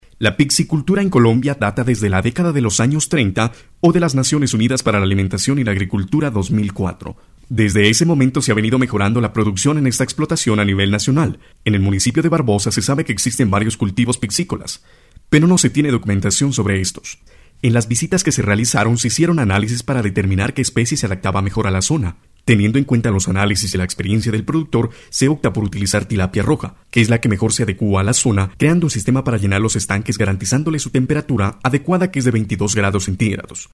kolumbianisch
Sprechprobe: Sonstiges (Muttersprache):